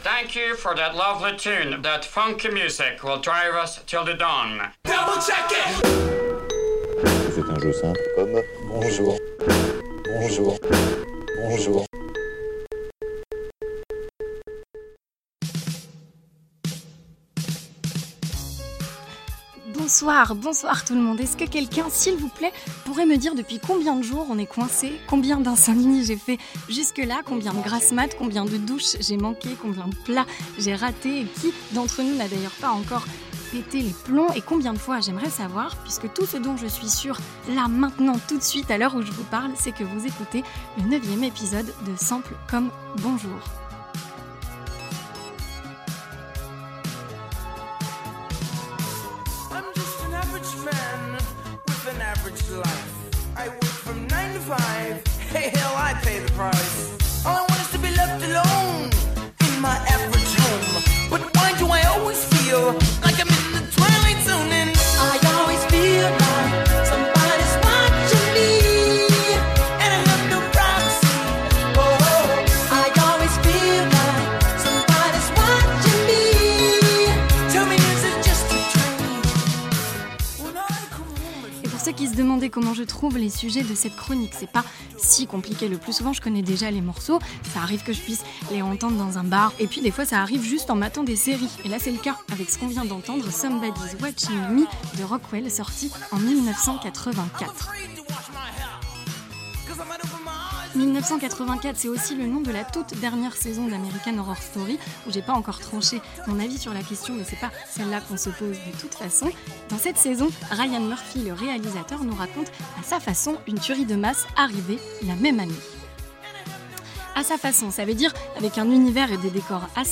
Du synthé 80's, une ambiance de château hanté, des costumes colorés, Michael Jackson au refrain...